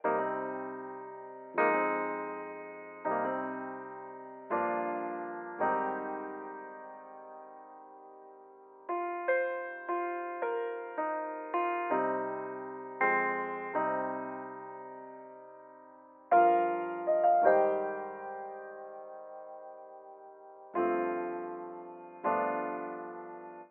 11 piano B.wav